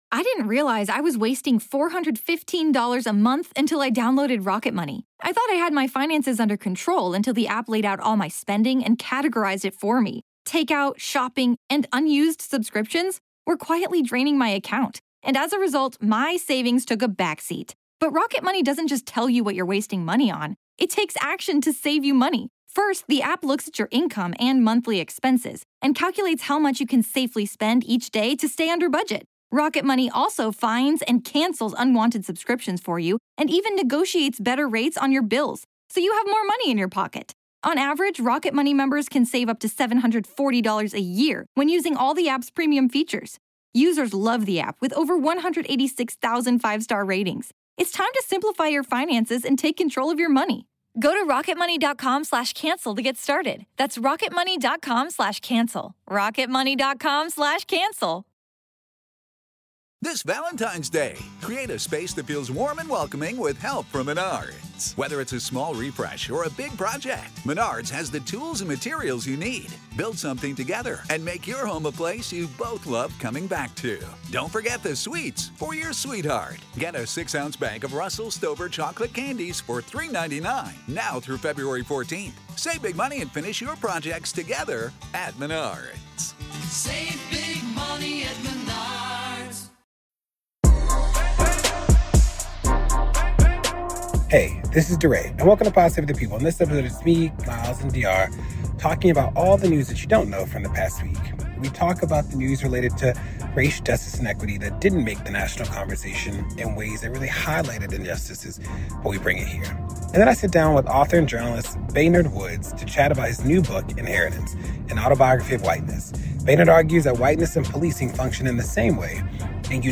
DeRay interviews author and journalist to chat his new book Inheritance: An Autobiography of Whiteness.